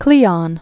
(klēŏn) Died 422 BC.